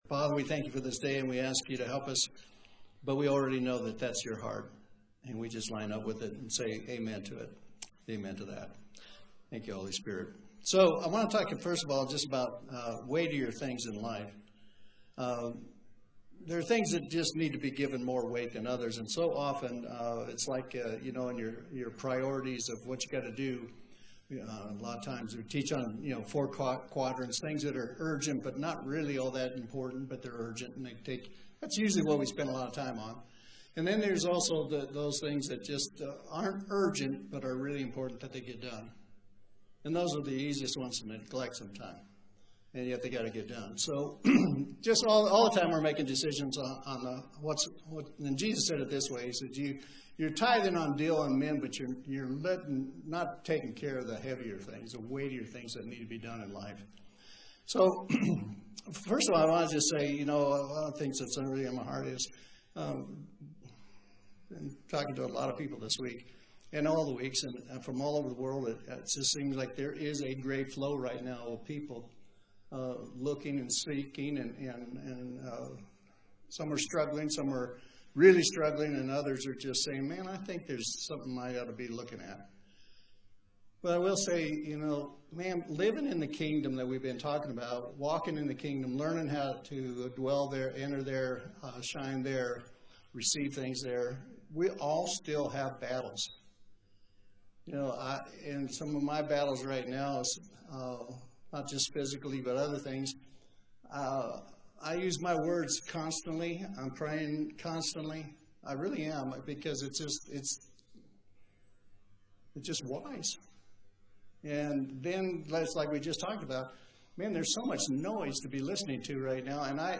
11-02-25 The Passion of God (Poor Audio)